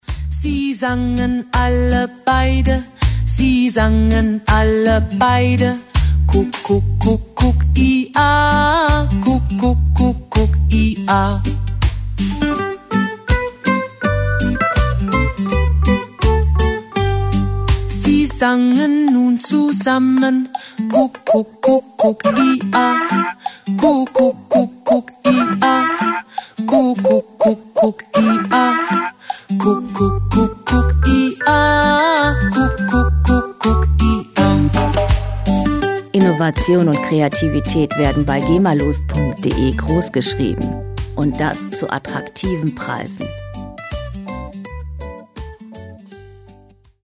• Kinderlied im Reggae Stil